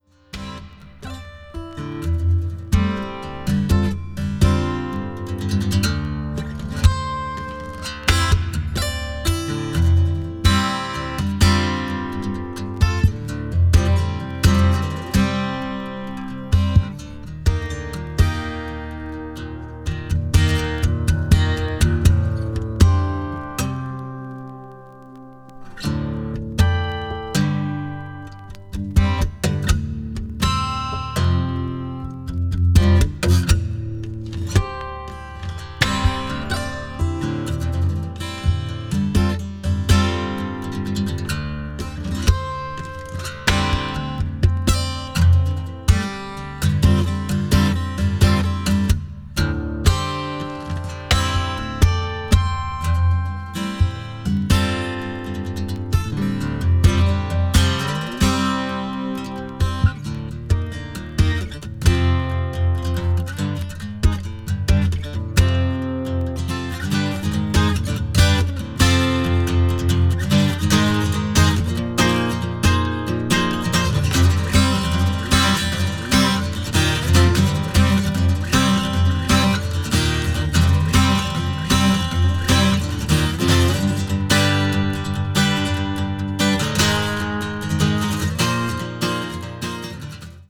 acoustic   guitar solo   neo country   neo folk   new age